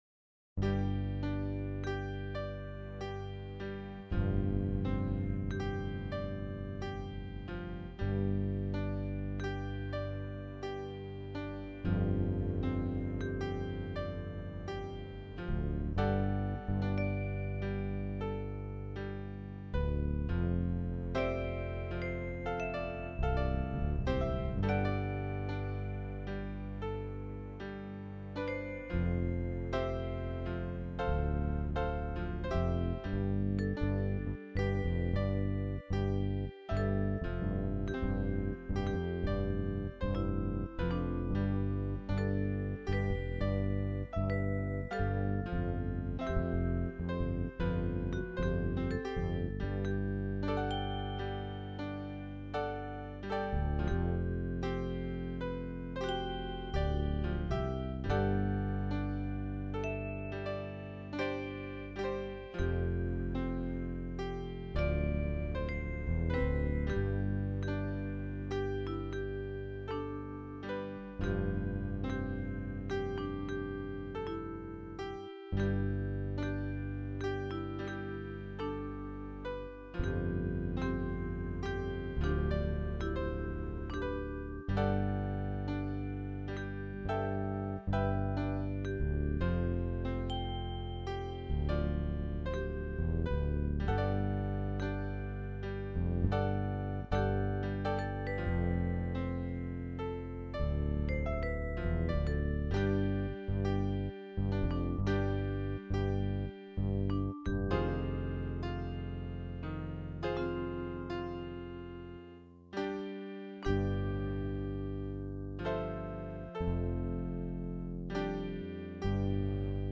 Piano Melody with Vipraphone and Fretlass Bass